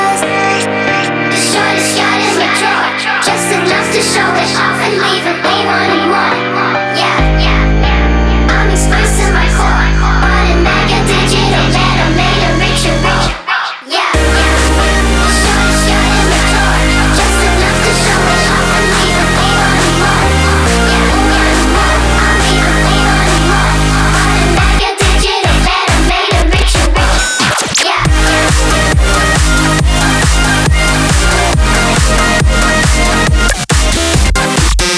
2025-08-08 Жанр: Танцевальные Длительность